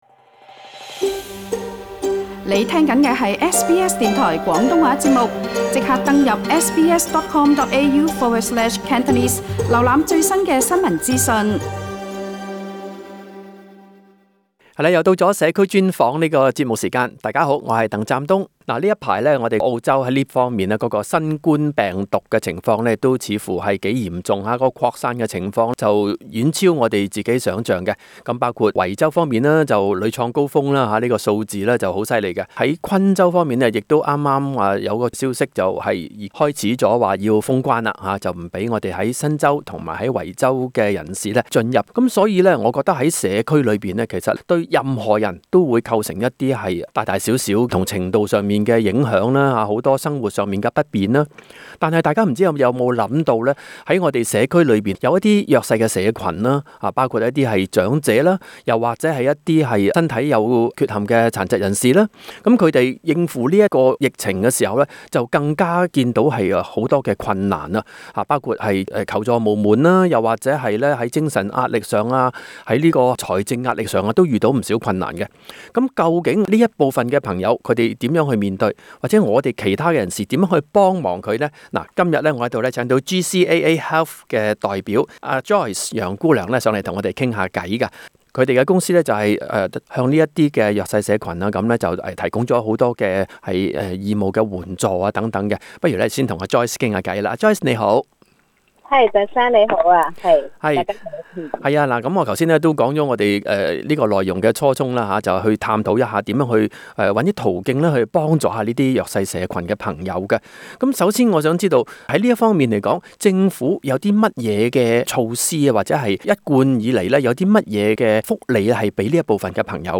【社區專訪】如何輕鬆申請長者家居護理服務